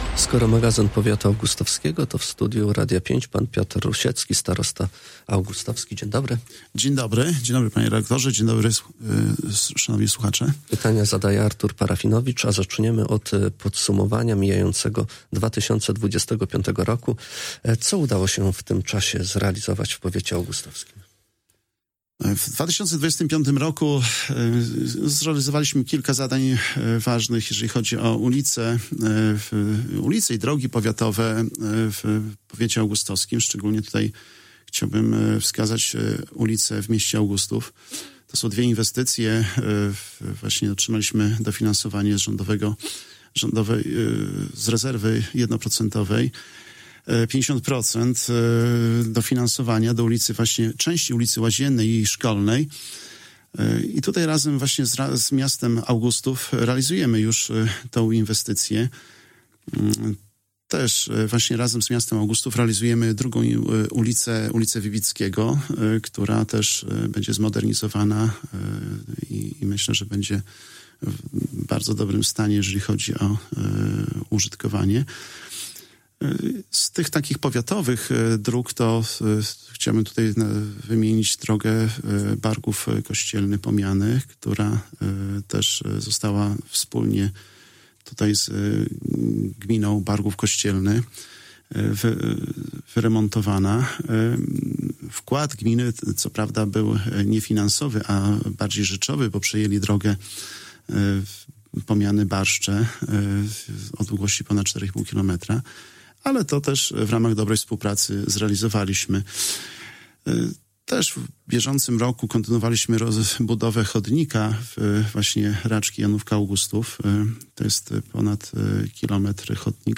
O zrealizowanych i planowanych inwestycjach mówił w Magazyn Powiatu Augustowskiego, Piotr Rusiecki, starosta augustowski.